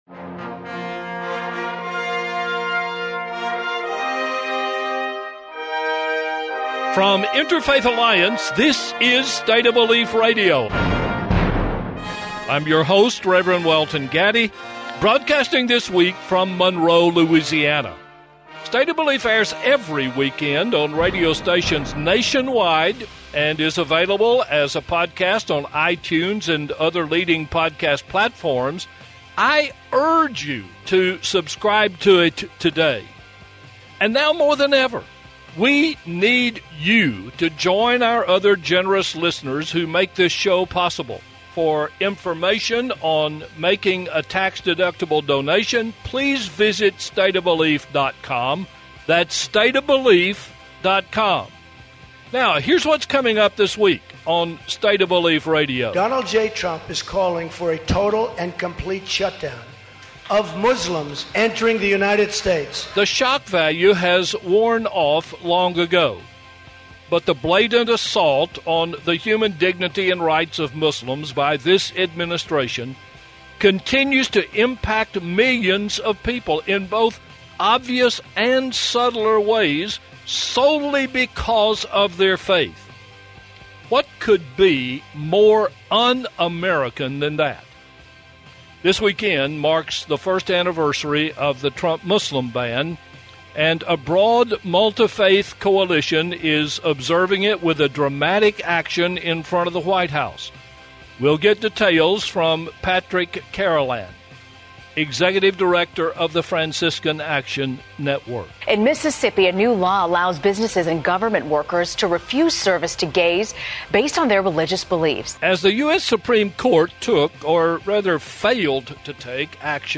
This week on State of Belief, Interfaith Alliance’s radio show and podcast, we’ll take a look back at the Muslim Ban and the Women’s March. We’ll also dig into a recent Supreme Court (non)decision related to anti-LGBT measures disguised as a protection of religious freedom – and what that decision means about the state of religious liberty.